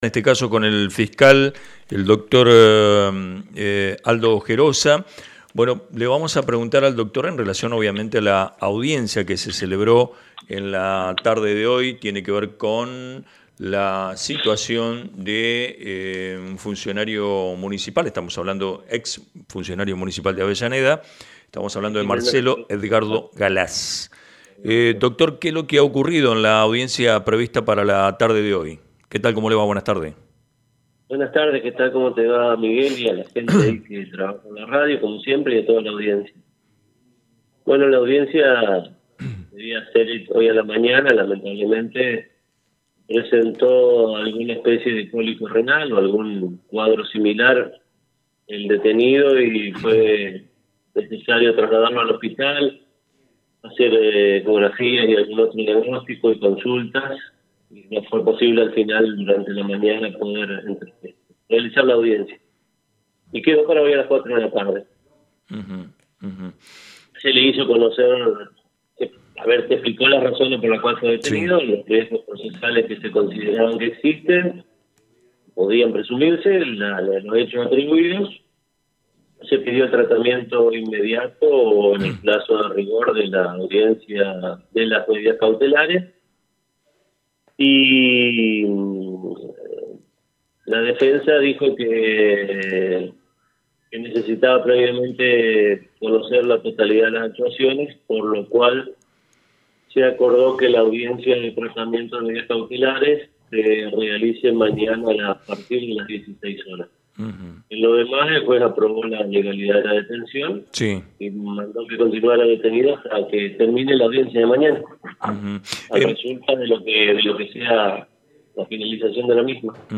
Audio del Fiscal
19-07-Nota-Aldo-Gerosa.mp3